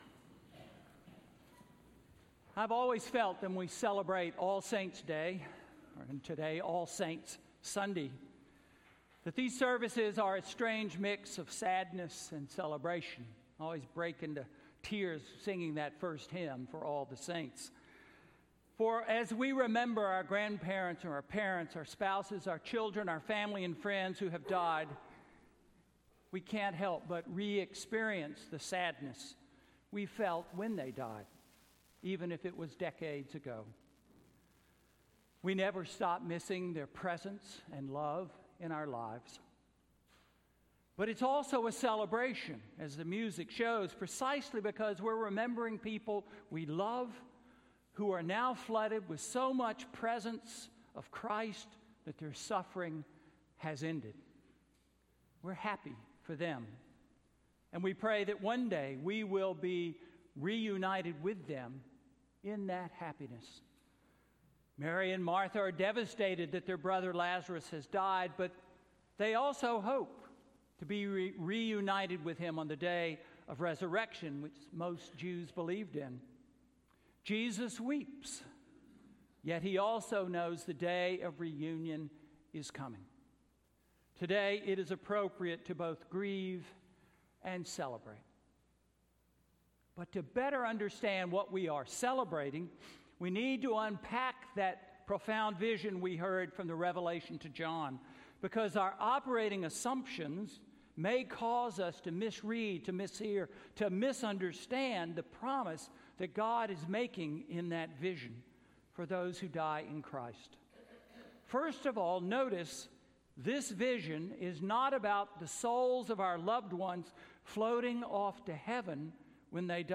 Sermon–All Saints? Really? November 4, 2018
All Saints’ Sunday–November 4, 2018